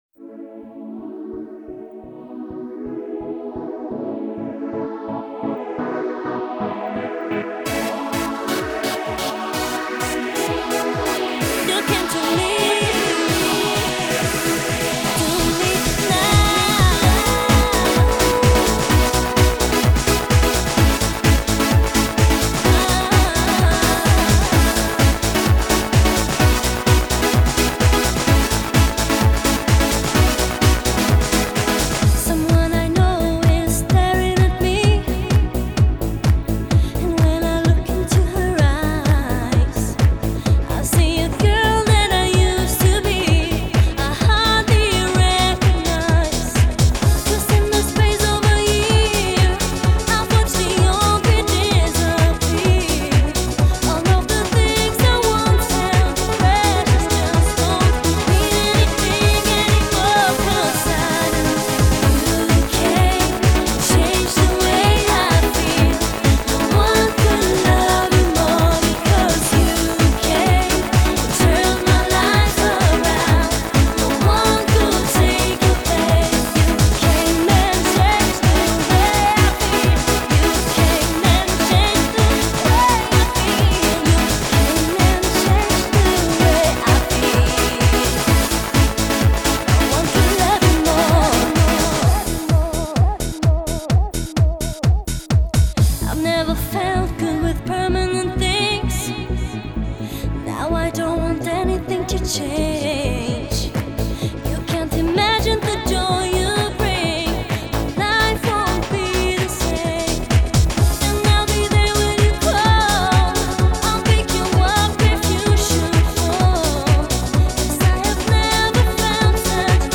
Genre: Dance.